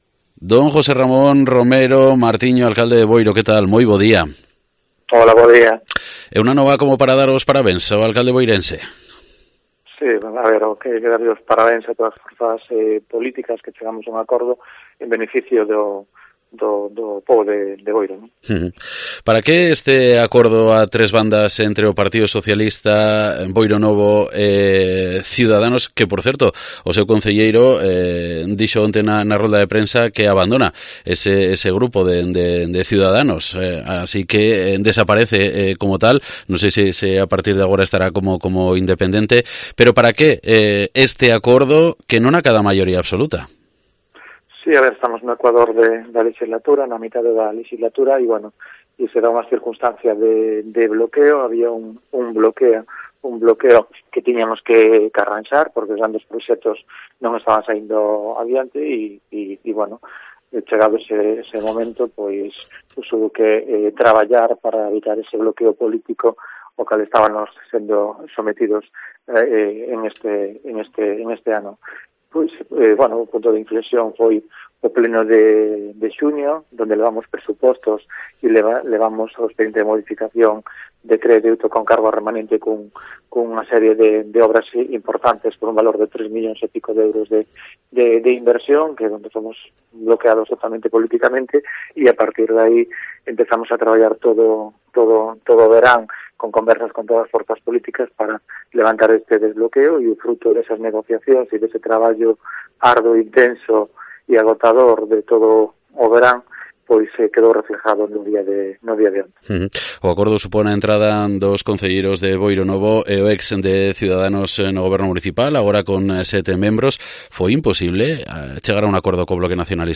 Entrevista en COPE de las Rías con el alcalde de Boiro, José Ramón Romero